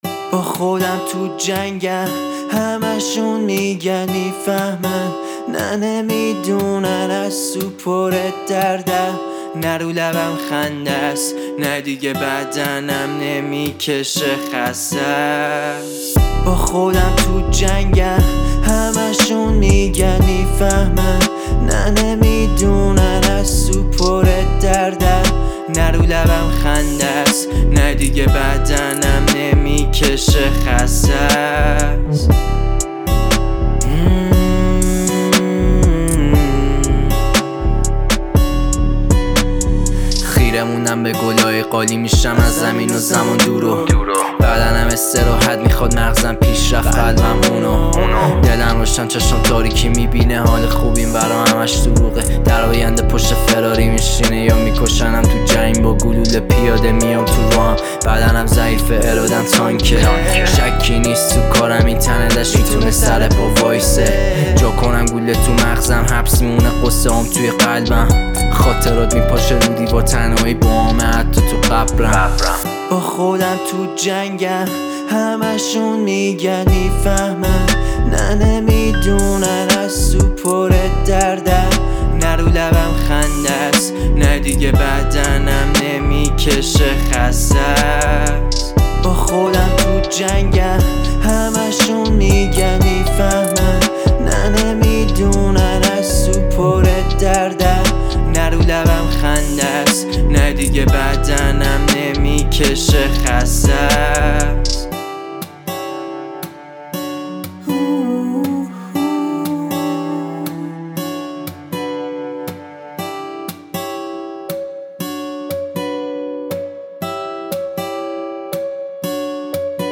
حس و حال آرام و متفاوت